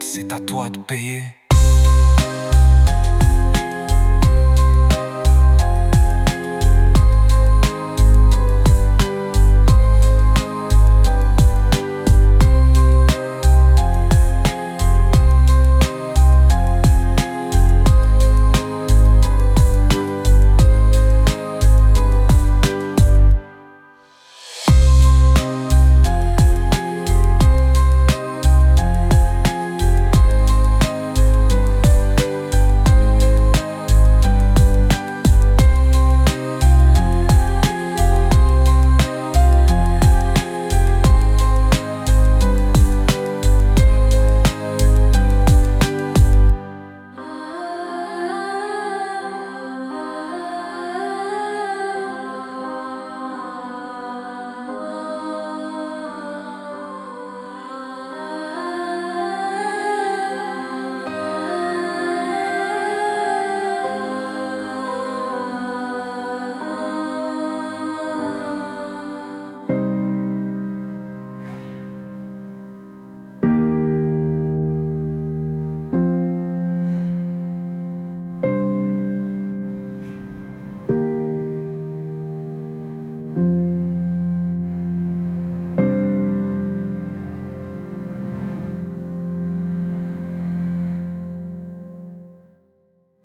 Alternative 2 : Piano et chœurs mystiques